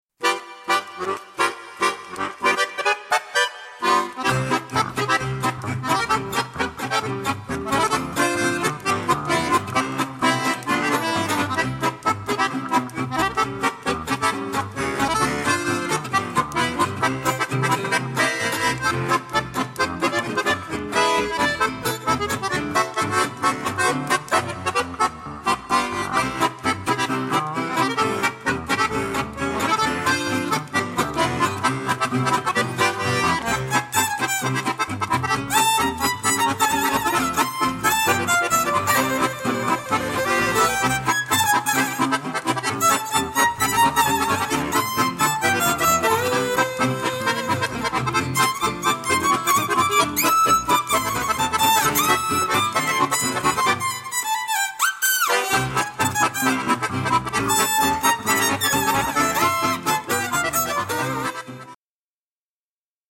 Swing Manouche, Musette, Balkan, Tango`s & Osteuropa ...
Traditionell Swing